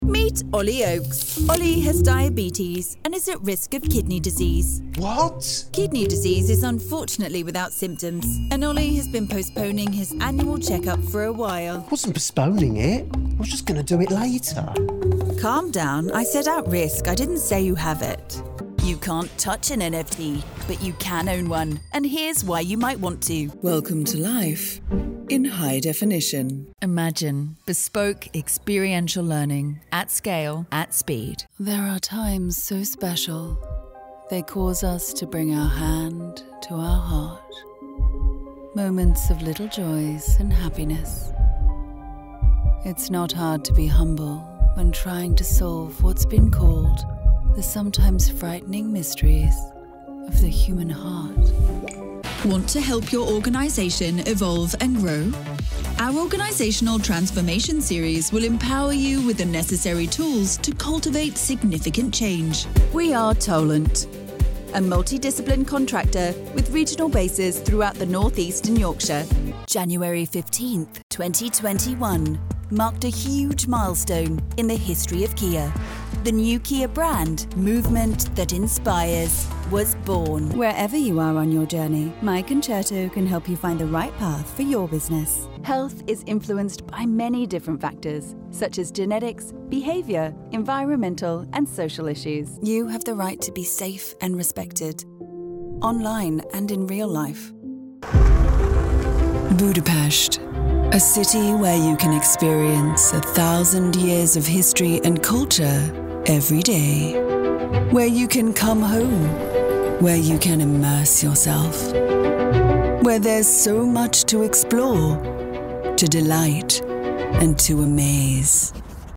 Warm, clear and seriously experienced...
English - United Kingdom
My voice has a conversational, soothing maturity- my voice's natural warmth will bring depth to your script.